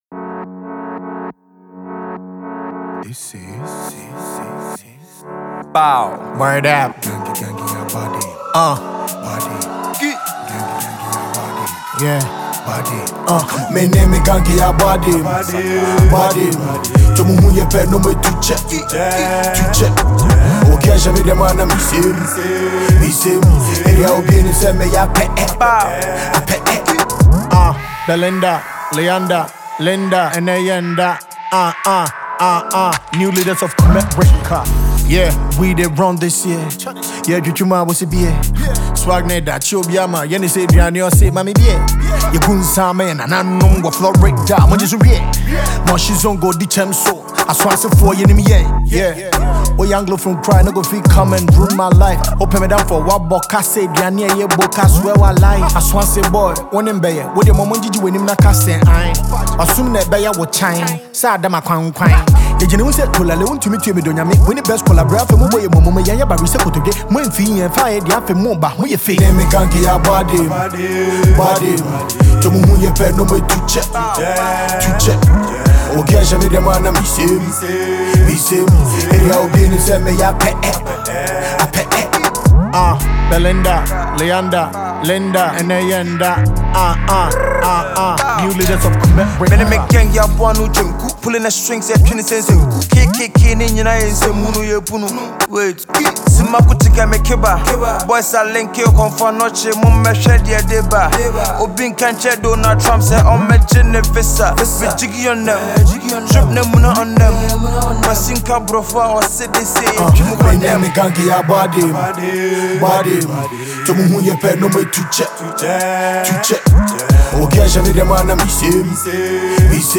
drill song